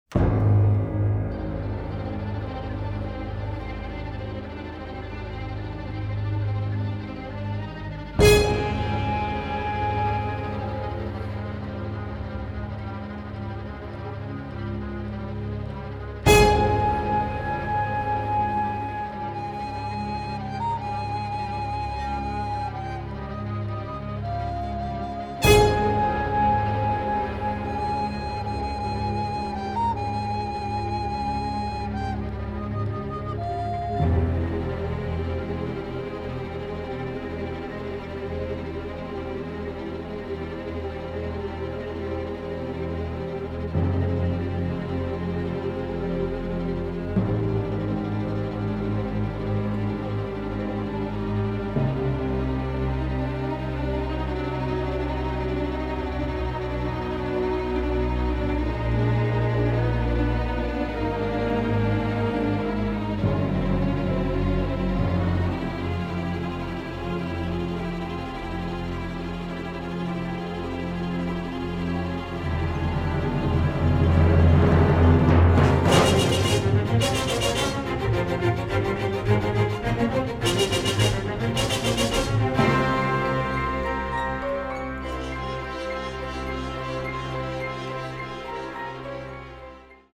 The Film Score